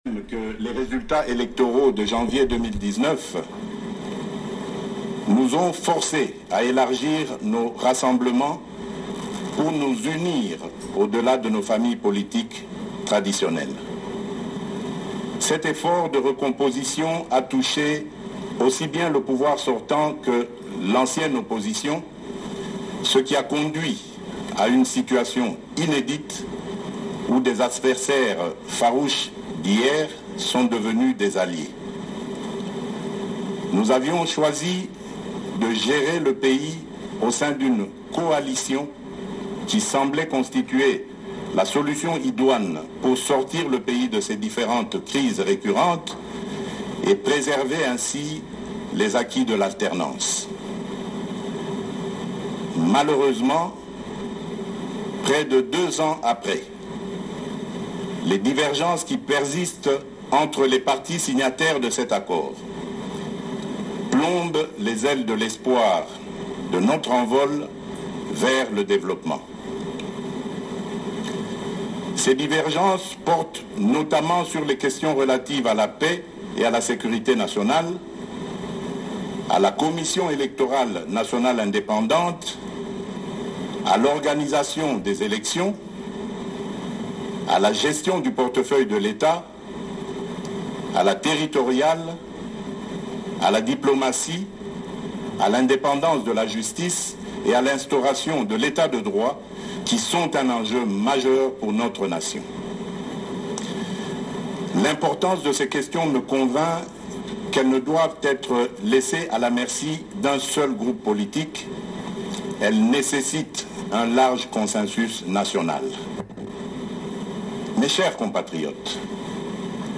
Dans son discours à la nation vendredi 23 octobre, le président Felix Tshisekedi a épinglé les blocages que connait la coalition FCC-CACH dans la gestion de la RDC. Il annonce qu’il va mener des consultations auprès de différentes nationales en vue d’aboutir à un large consensus sur les grandes questions du pays.
web-adresse_du_chef_de_letat_felix_tshisekedi_a_la_nation.mp3